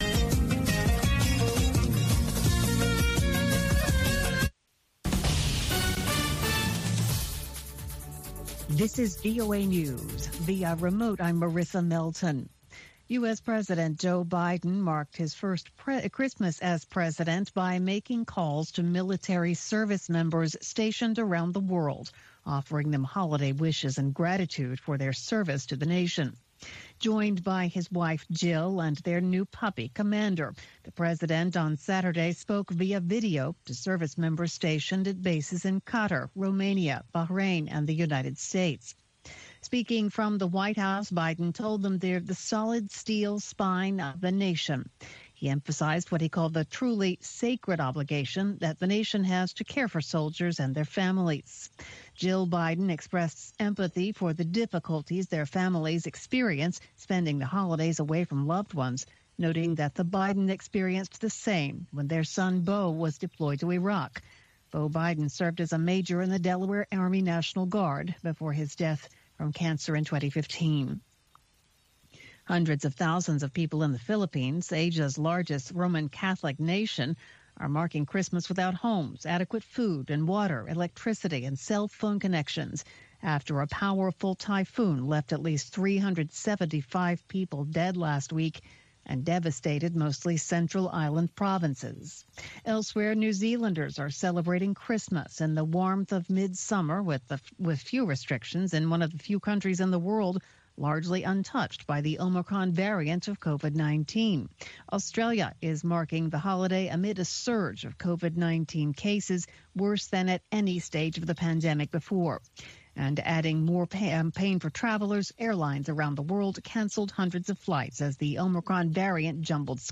هەواڵەکانی 3 ی پاش نیوەڕۆ